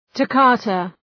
Προφορά
{tə’kɒtə}